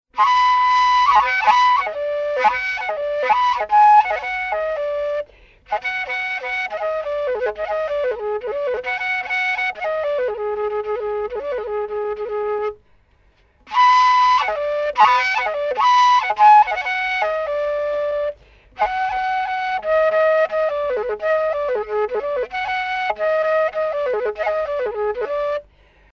Musique pastorale
Flûte droite à encoche
Burundi